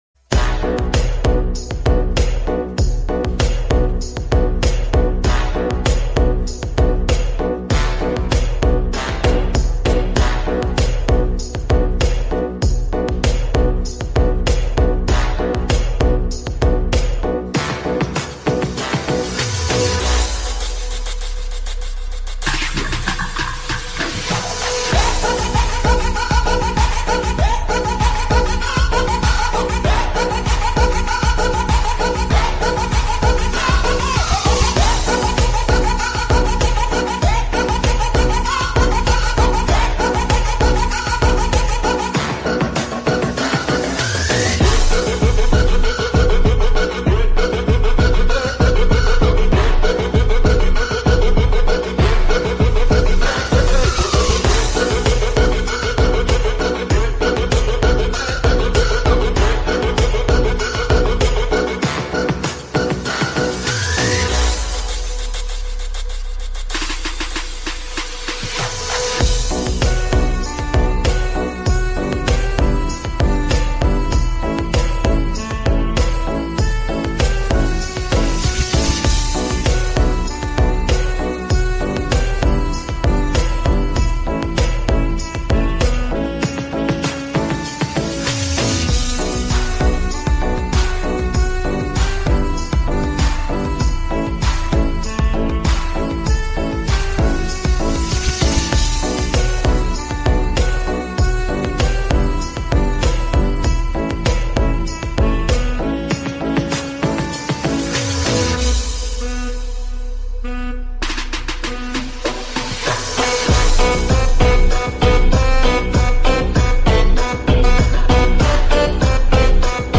Saab JAS 39 Gripen Fighter Jet sound effects free download
Saab JAS-39 Gripen Fighter Jet Supersonik